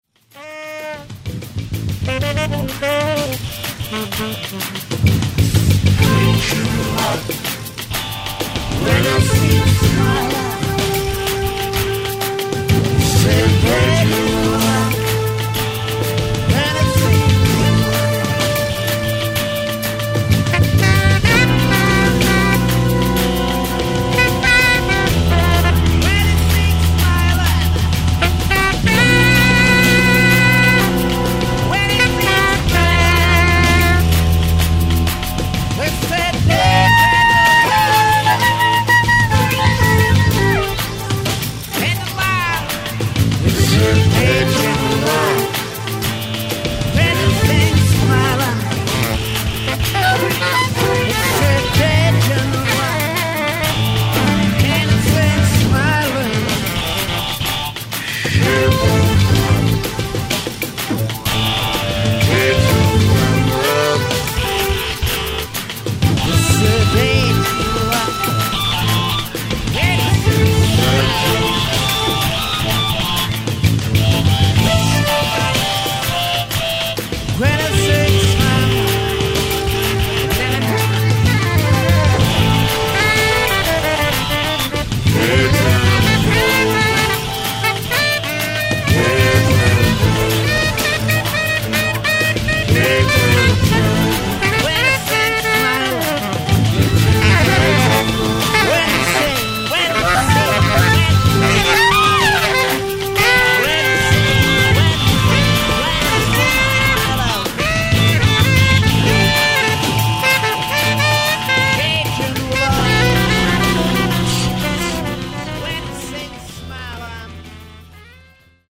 recorded and mixed at bildbad studio and atelier küpfendorf